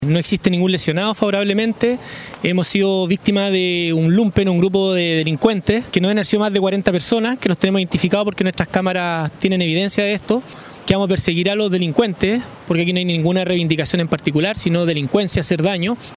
El alcalde de la comuna, Christopher White, señaló que los hechos son delincuenciales.